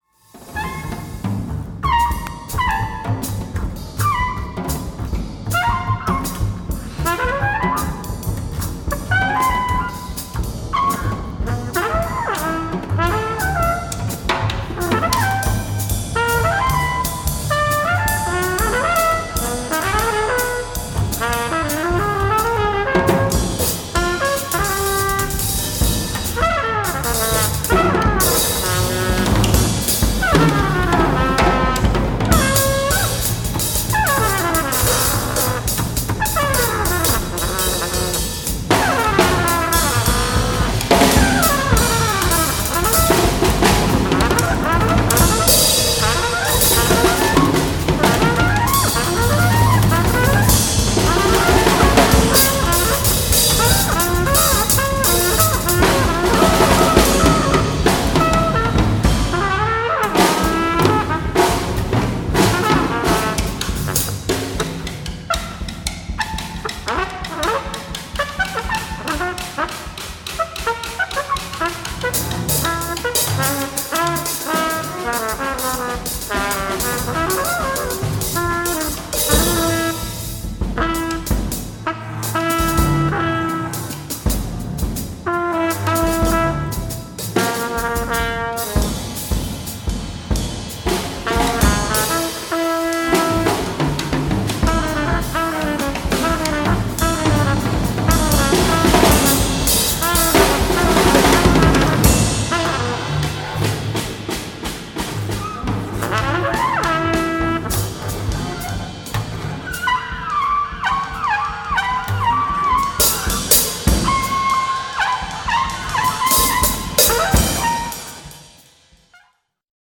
フリージャズ、インプロヴィゼーションと、創造力に富んだ3つの深淵な会話的演奏を聴くことが出来ます。